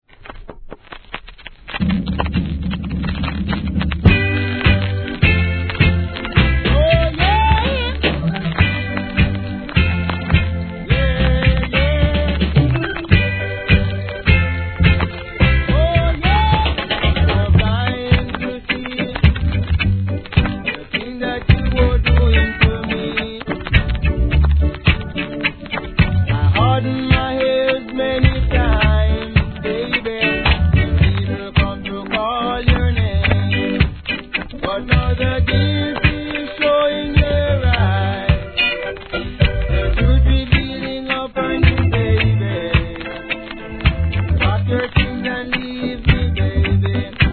序盤にチリ目立ちますが徐々に落ち着きます
REGGAE